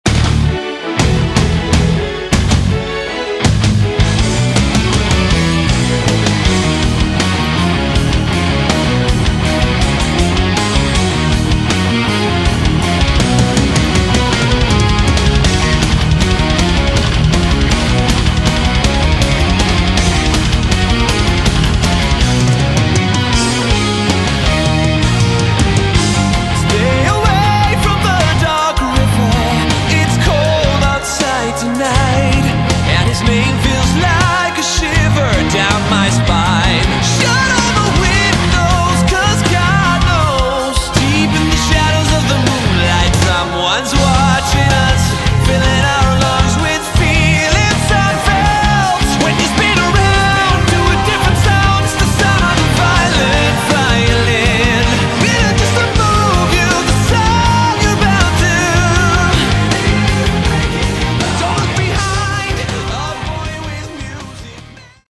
Category: Prog Rock
bass
guitar
vocals
keyboard
drums